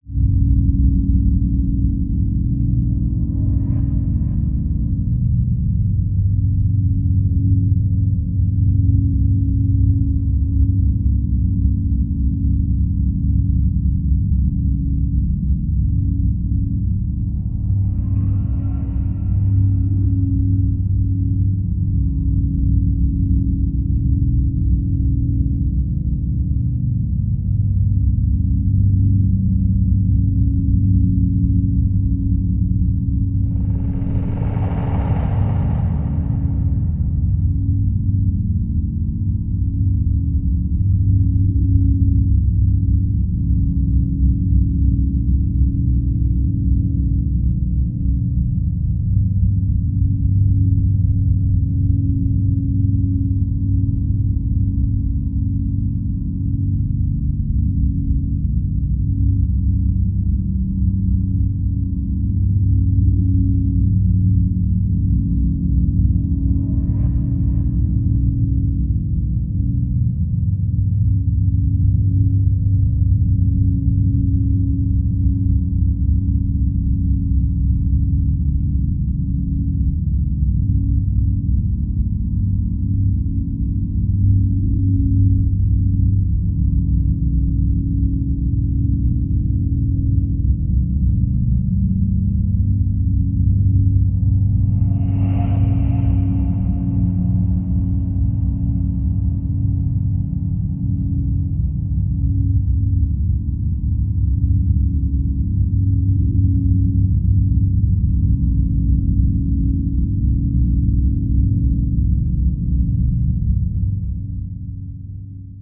free horror ambience 2
ha-undercurrent2.wav